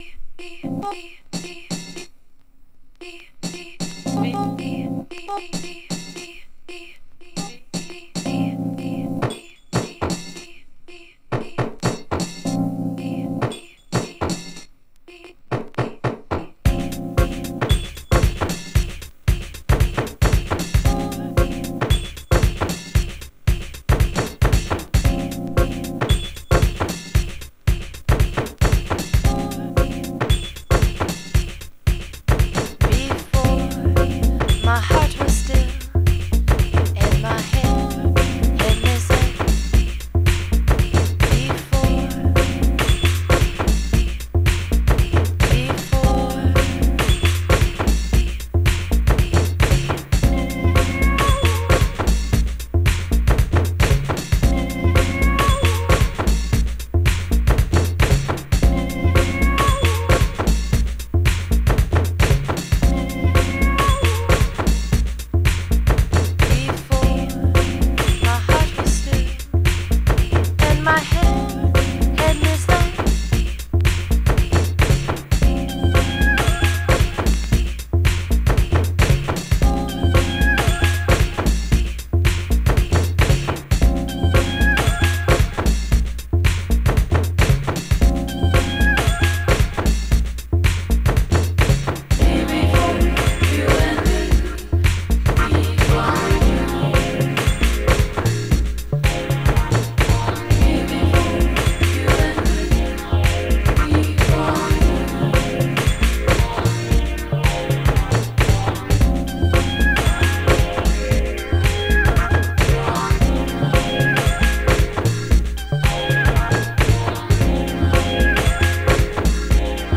先鋭的かつセンシュアルなモダンハウス・リミックス集。